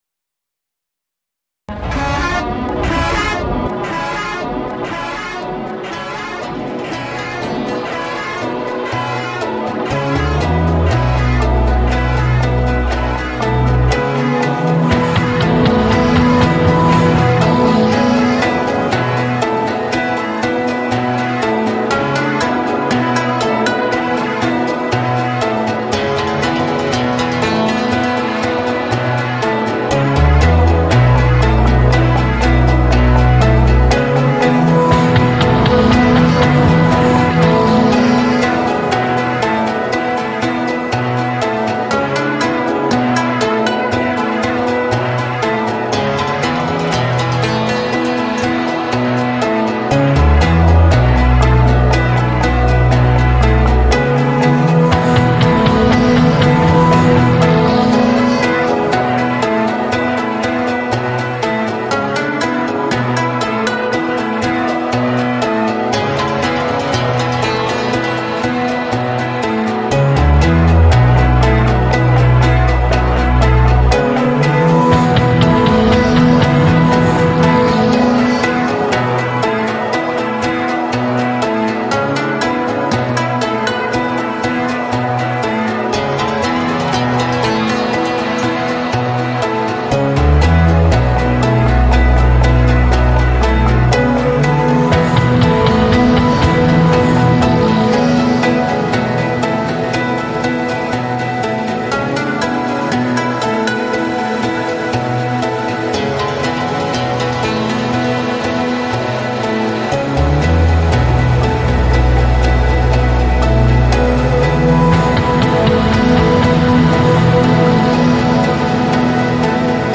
Musical instrument on the Great Wall of China reimagined